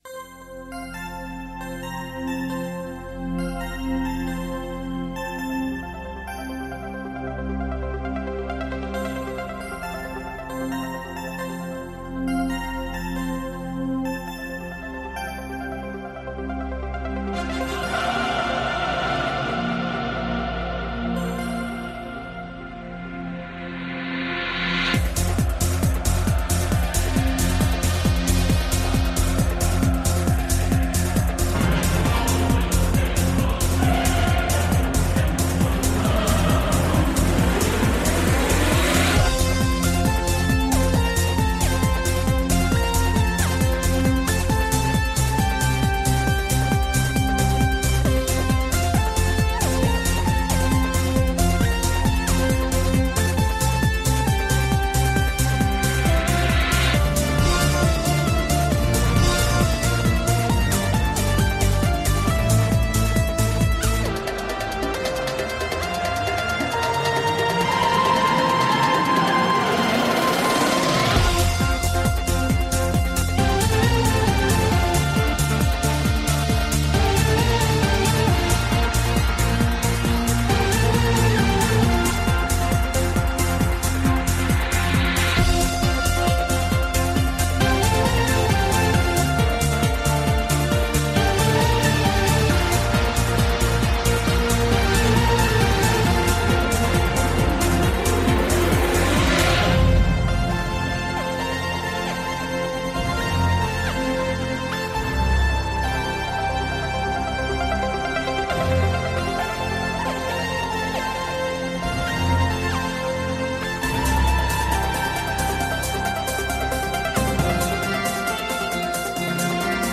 - Elkarrizketa